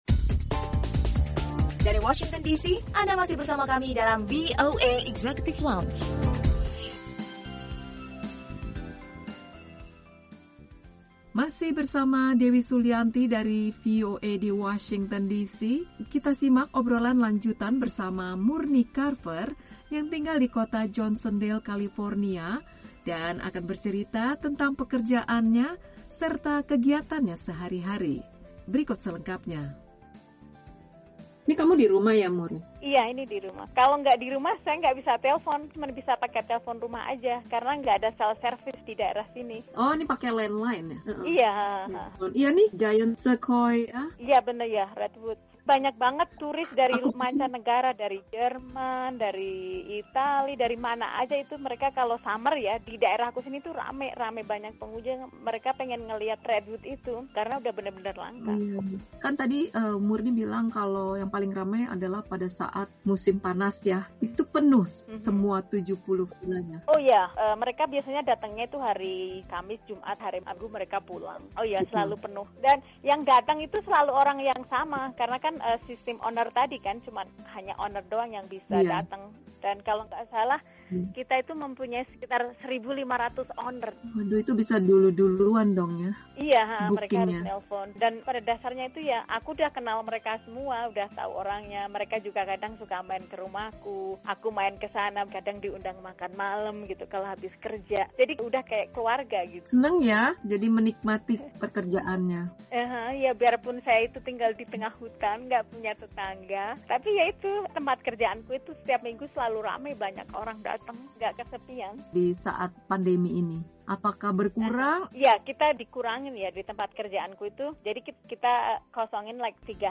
Obrolan lanjutan